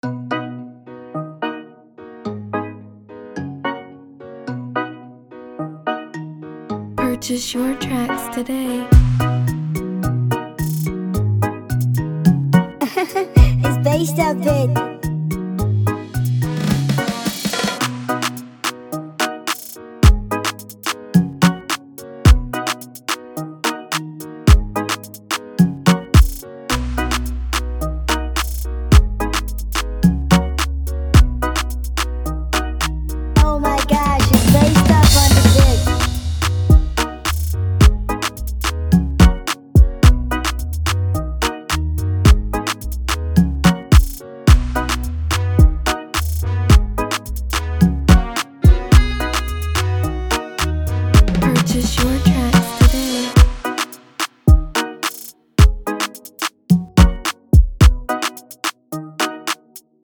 With its captivating melody and irresistible groove